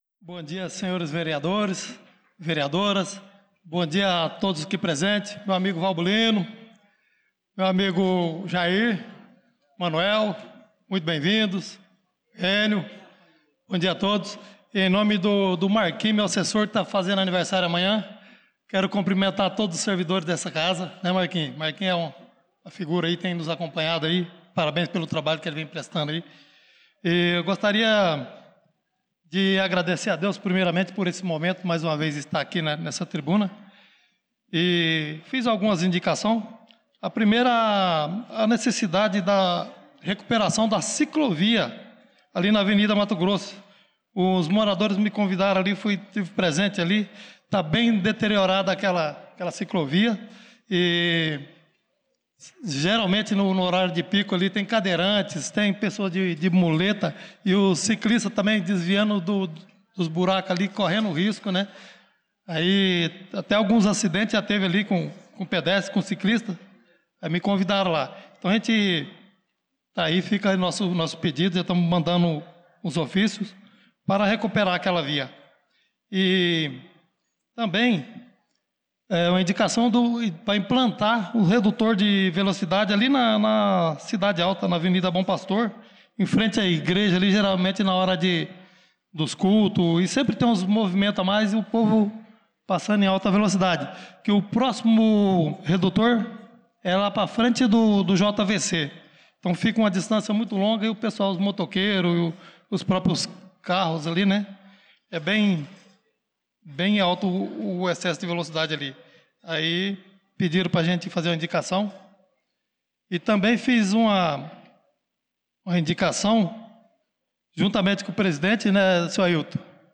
Pronunciamento do vereador Chicão Motocross na Sessão Ordinária do dia 01/04/2025